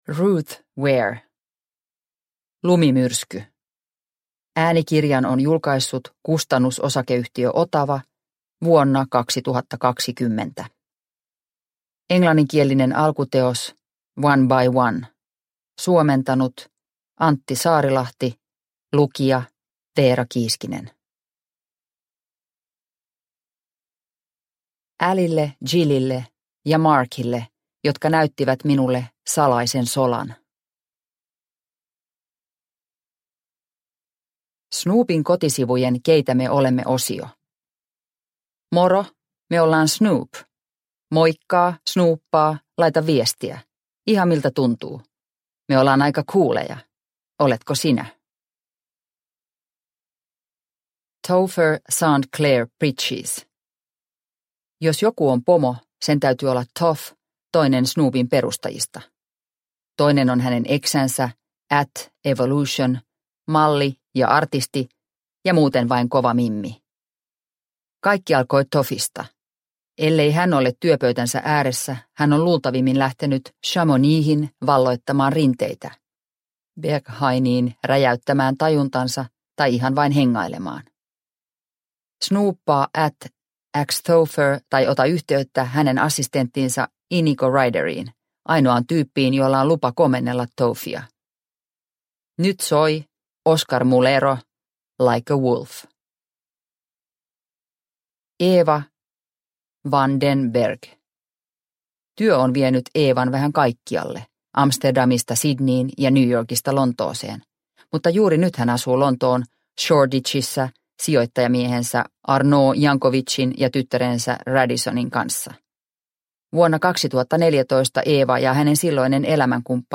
Lumimyrsky – Ljudbok – Laddas ner